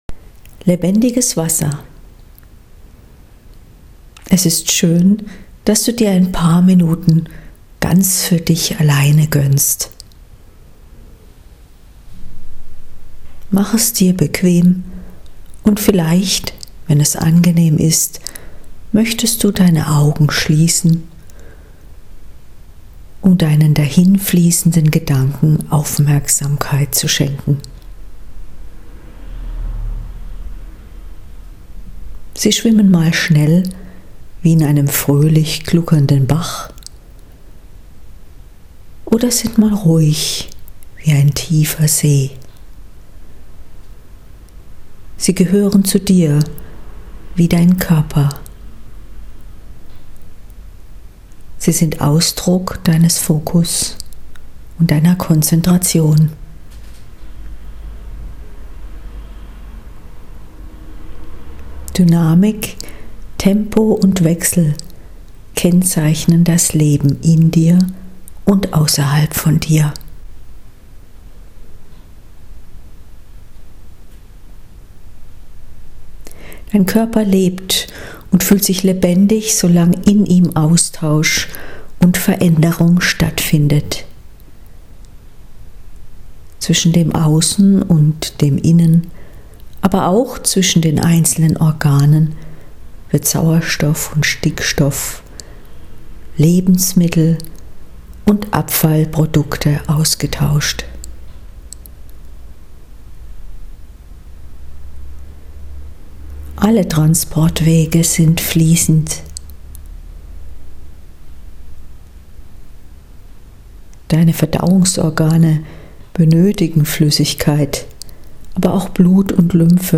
Für all jene Leser, die gerne 5 Minuten ihrer Zeit dafür widmen wollen die Rolle des Wassers und der Flüssigkeiten in einem gesunden Körper zu wertschätzen habe ich die folgende kleine Meditation gemacht.